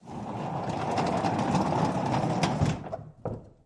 Doors-Wood
Barn Door, Slide Shut